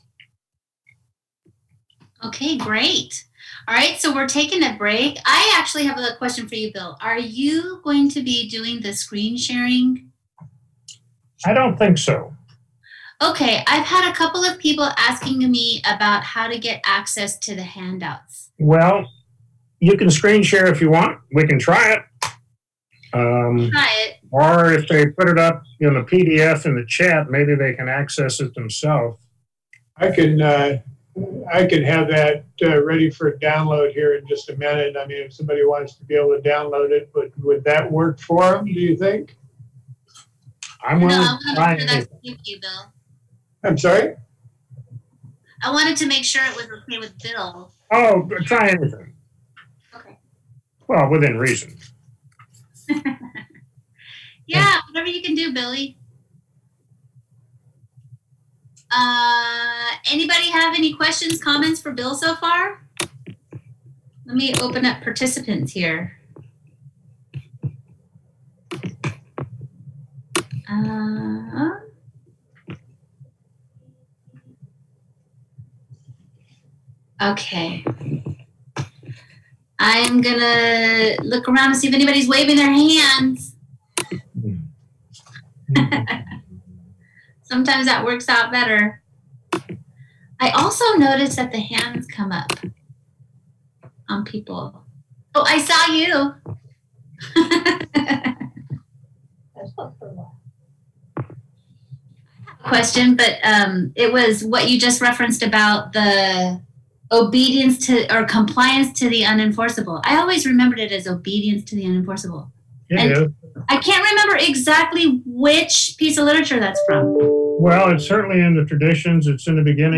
Emotionally Sobriety - AWB Workshop